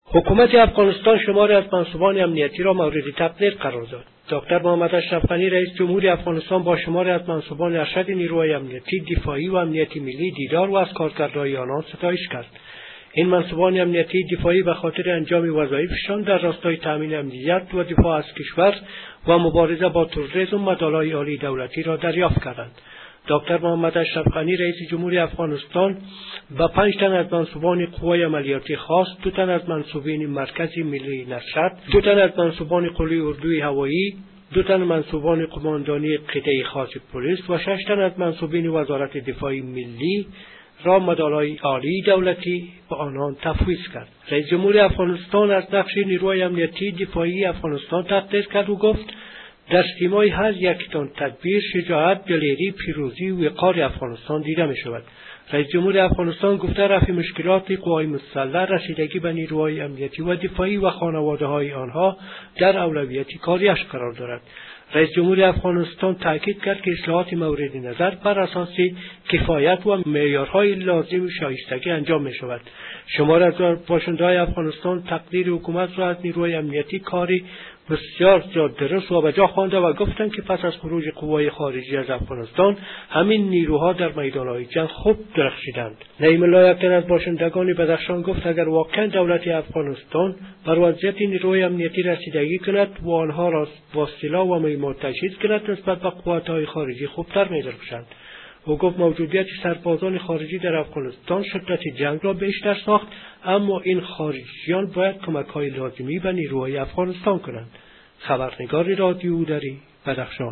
گزارش : تقدیر حکومت افغانستان از برخی مقامات امنیتی این کشور